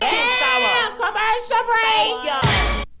Vox